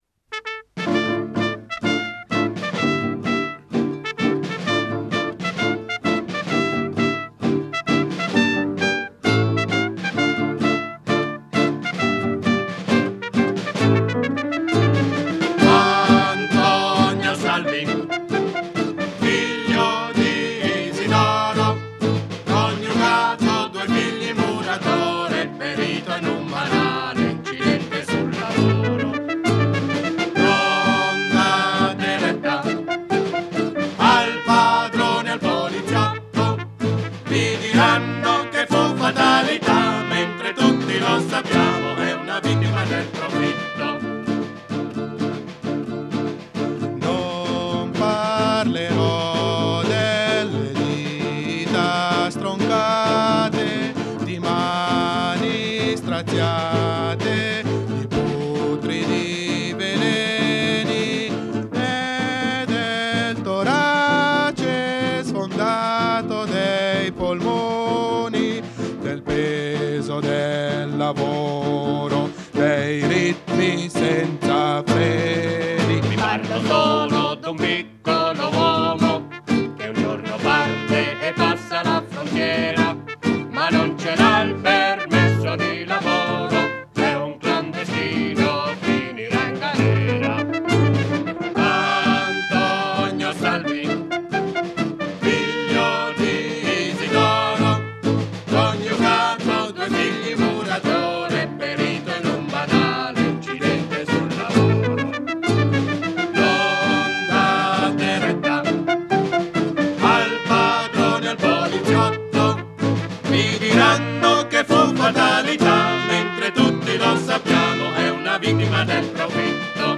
pianoforte
chitarra
tromba
batteria